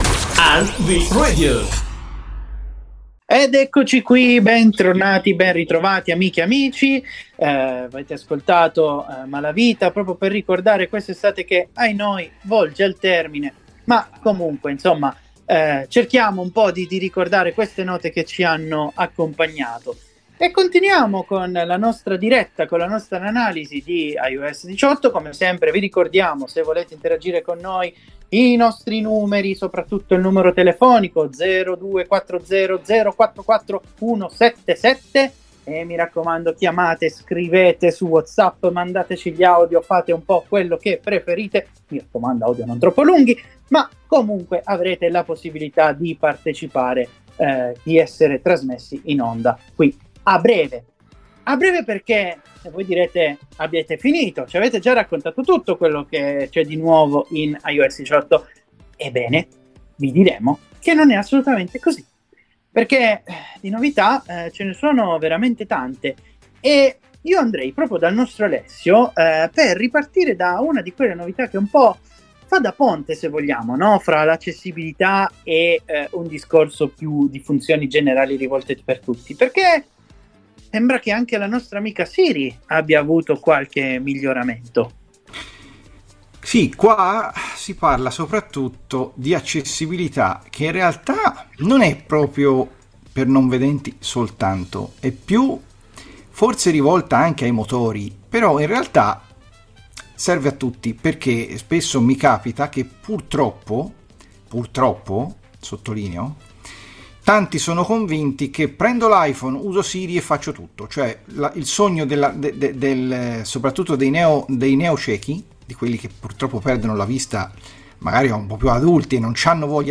seconda parte della diretta NvRadio del 17 settembre. Abbreviazioni vocali, Centro di controllo, app password, telefono e t9, schermata di blocco, memo vocali, app foto, domande degli ascoltatori